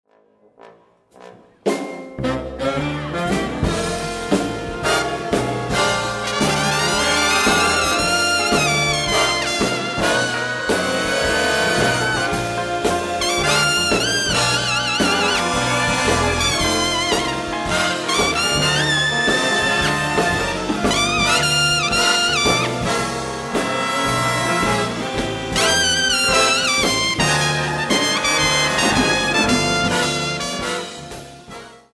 a swinging blues including a spot for bass trombonist